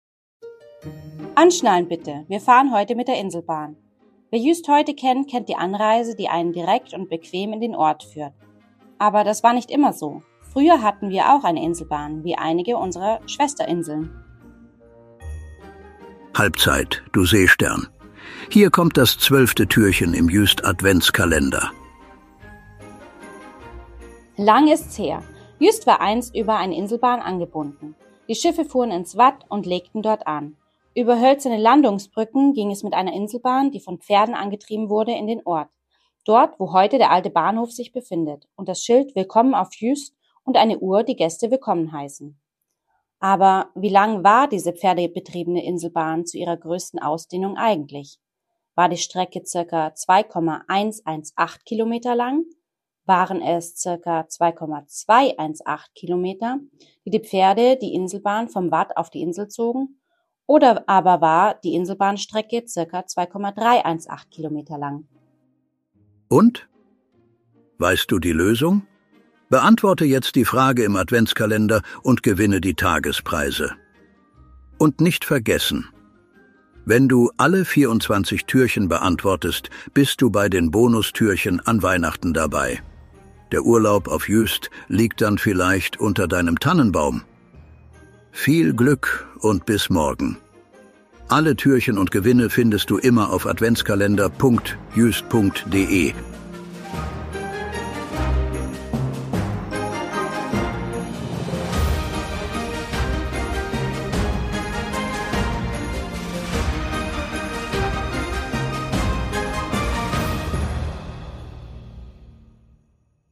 Eingesprochen wird der Adventskalender von vier
guten Geistern der Insel Juist, die sich am Mikro abwechseln und